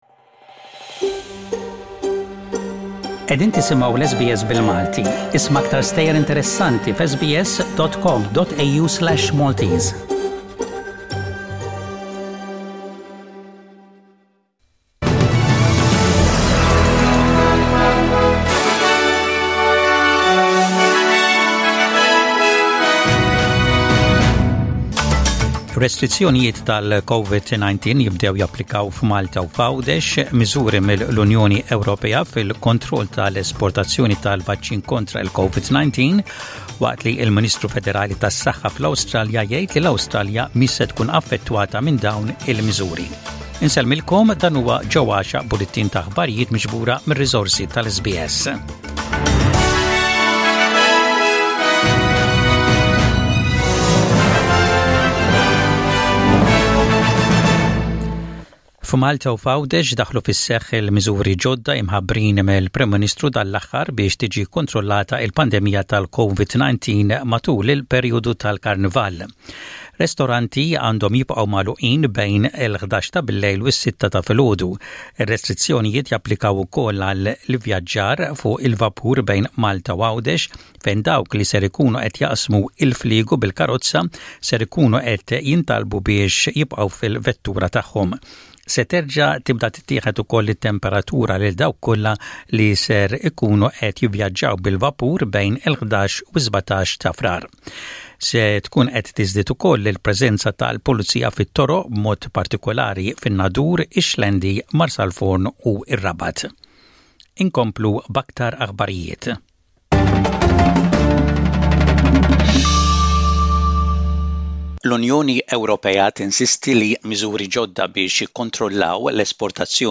SBS Radio | Maltese News: 02/02/21